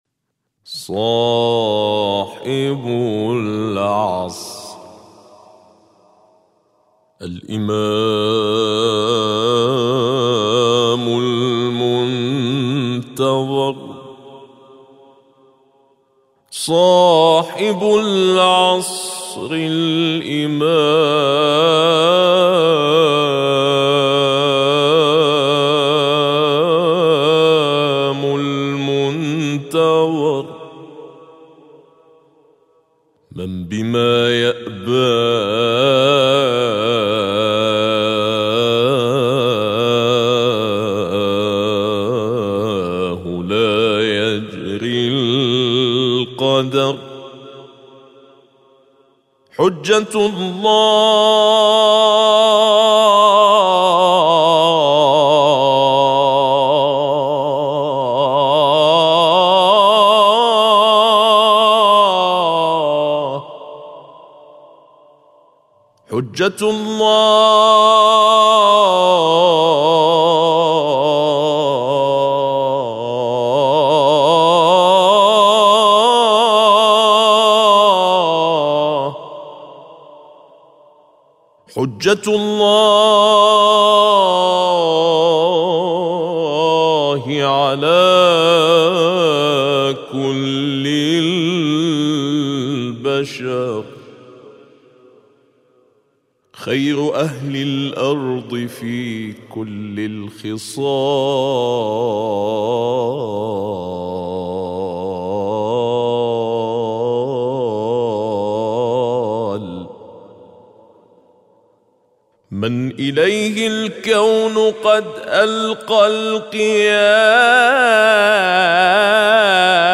مدائح للإمام المهدي (عج)